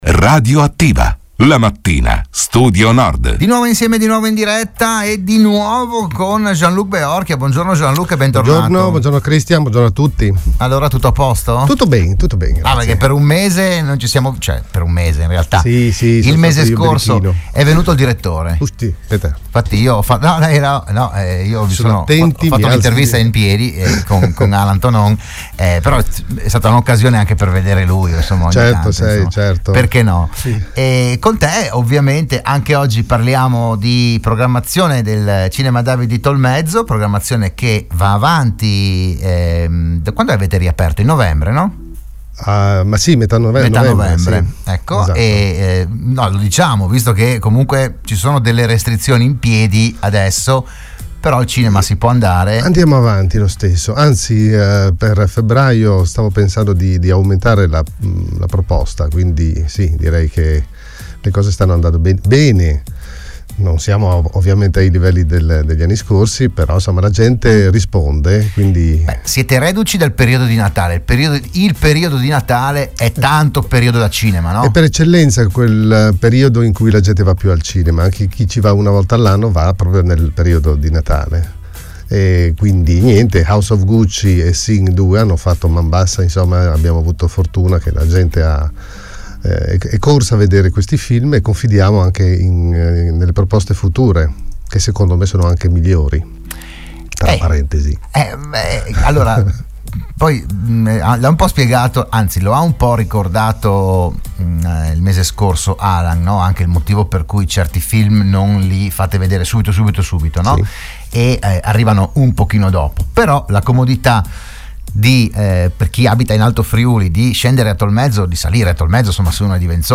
L'AUDIO e il VIDEO dell'intervento a Radio Studio Nord